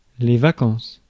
Ääntäminen
Synonyymit temps libre Ääntäminen Tuntematon aksentti: IPA: /va.kɑ̃s/ Haettu sana löytyi näillä lähdekielillä: ranska Käännös Substantiivit 1. vacaciones Muut/tuntemattomat 2. vacación {f} 3. receso {m} Suku: f .